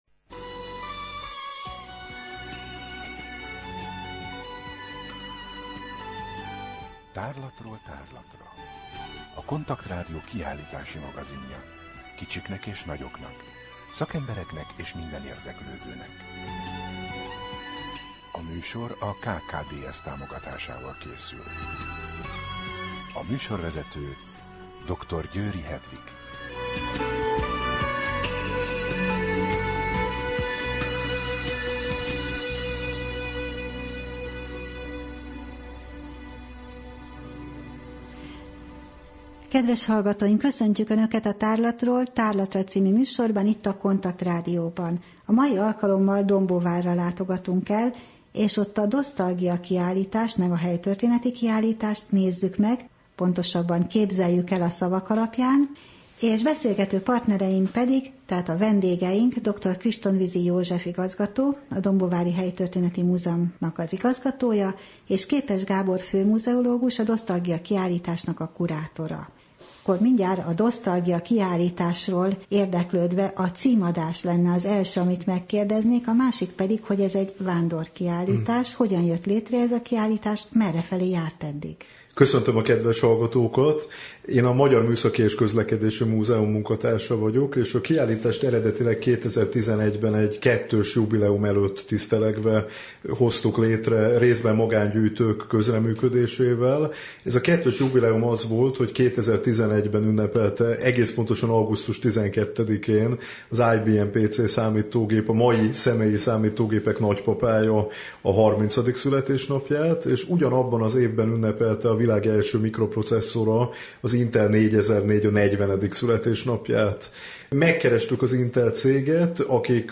Rádió: Tárlatról tárlatra Adás dátuma: 2013, Április 22 Tárlatról tárlatra / KONTAKT Rádió (87,6 MHz) 2013 április 22.